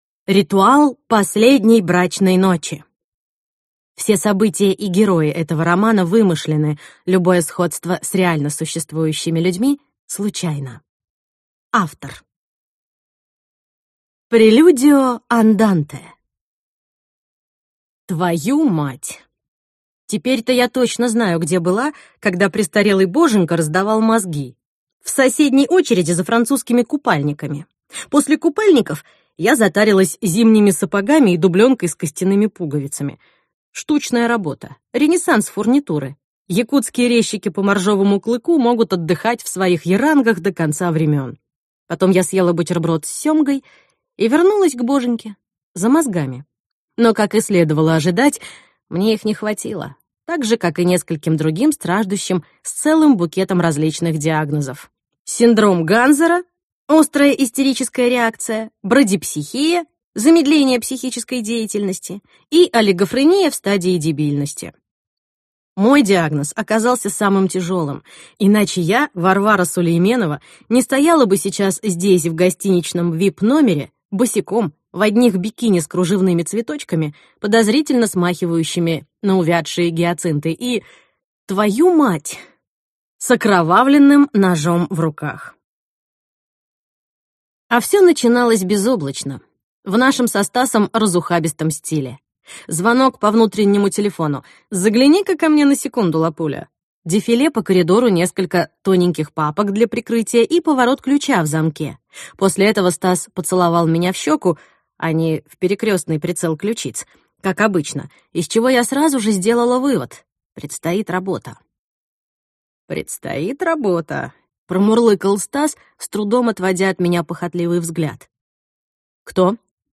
Аудиокнига Ритуал последней брачной ночи | Библиотека аудиокниг